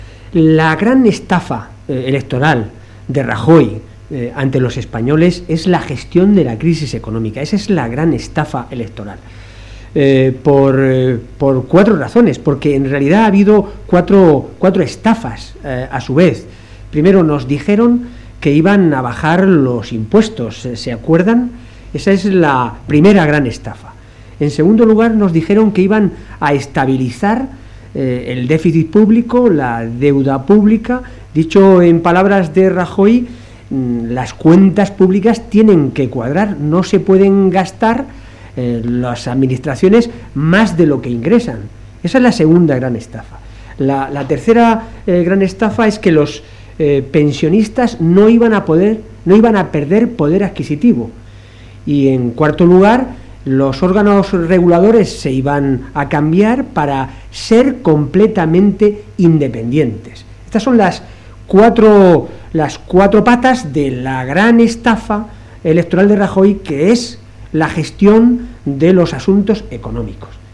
Fragmento de la rueda de prensa de Pedro Saura en la que denuncia la estafa electoral en la que ha incurrido Rajoy en la gestión de los asuntos econonómicos 13/09/2013